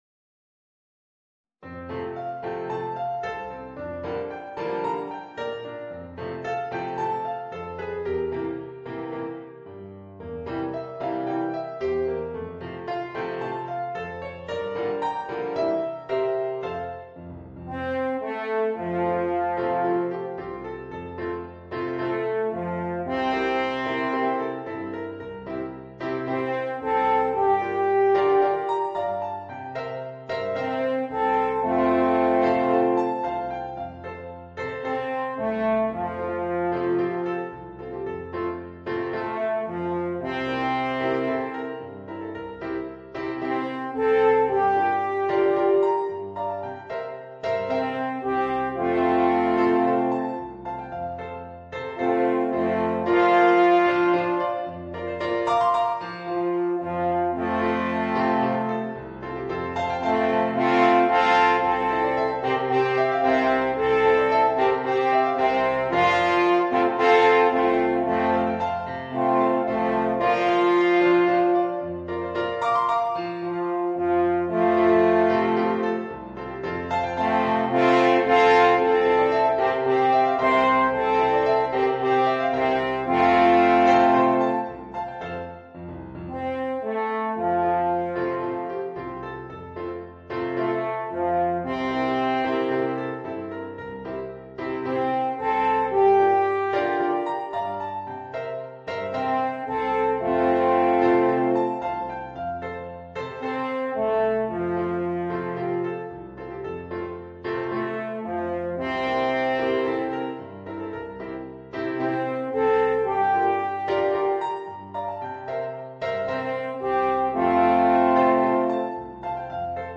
Voicing: Alphorn and Piano